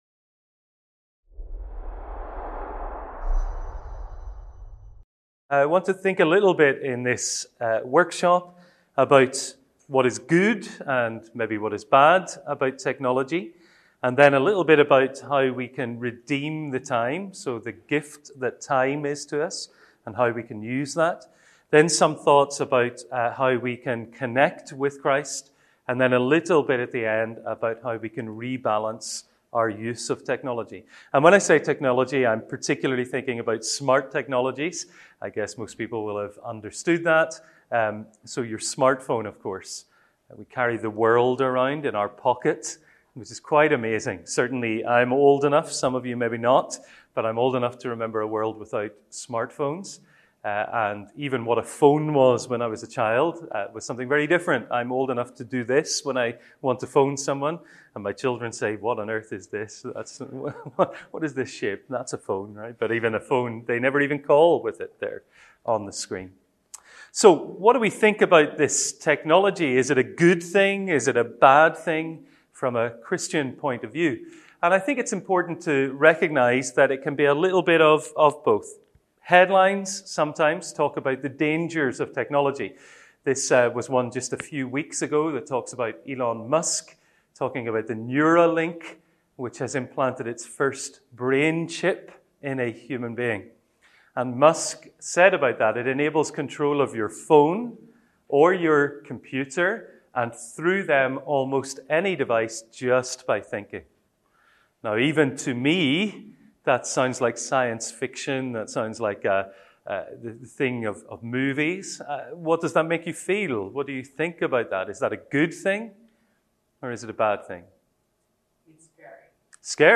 Event: ELF Workshop